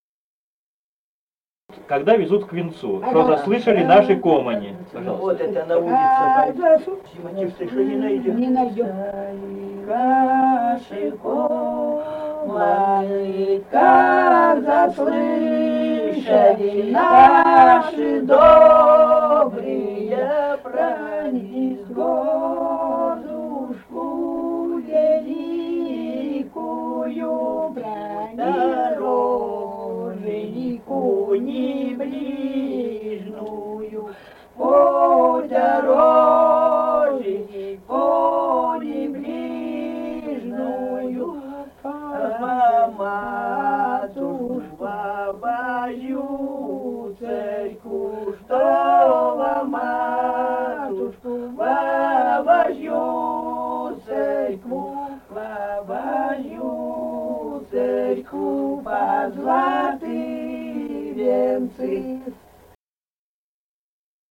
Русские песни Алтайского Беловодья 2 «Как заслышали наши ко́моны», свадебная, поют на улице, провожая жениха и невесту к венцу.
Республика Казахстан, Восточно-Казахстанская обл., Катон-Карагайский р-н, с. Урыль (казаки), июль 1978.